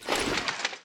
equip_iron1.ogg